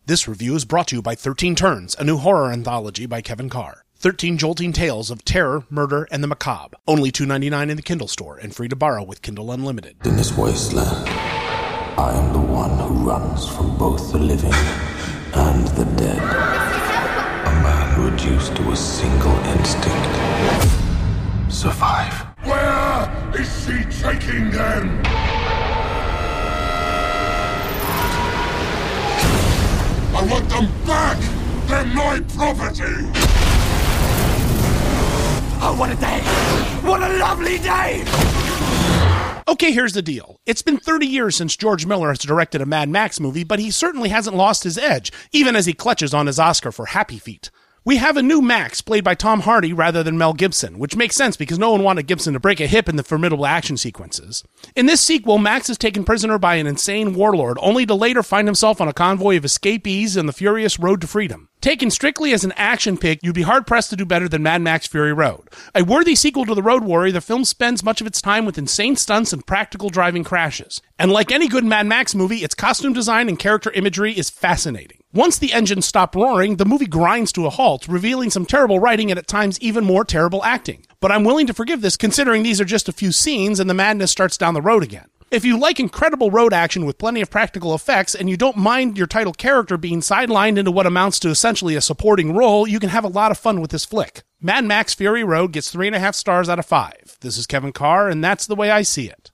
‘Mad Max: Fury Road’ Movie Review